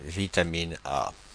Ääntäminen
Synonyymit rétinol Ääntäminen Paris: IPA: [vi.ta.min‿A] Tuntematon aksentti: IPA: /vi.ta.min‿a/ Haettu sana löytyi näillä lähdekielillä: ranska Käännös 1. vitamina A {f} Suku: f .